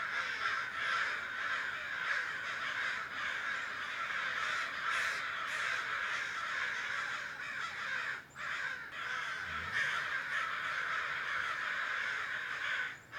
crows.ogg